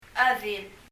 パラオ語 PALAUAN language study notes « ペリリューにて（2） father 父 » mother 母 chedil [? ə(æ)ðil] 英） mother 日） 母 Leave a Reply 返信をキャンセルする。